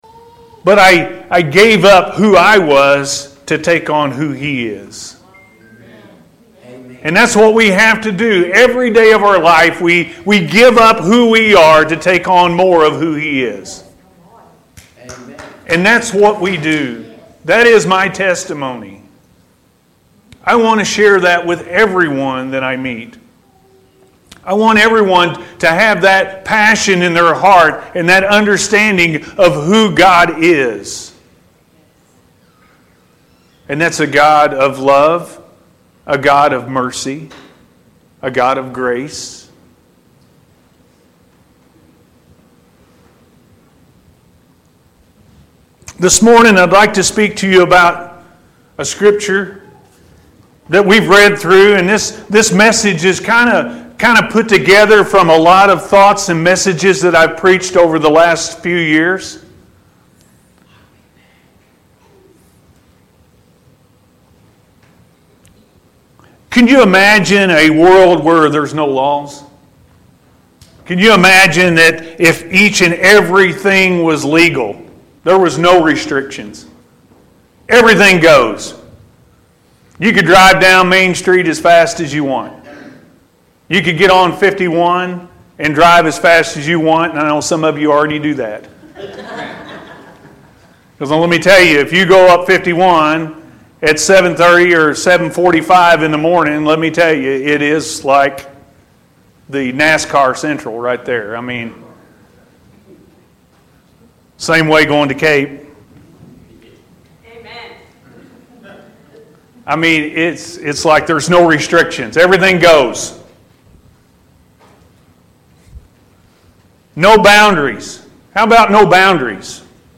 Don’t Be A Stumbling Block, Be Fruitful-A.M. Service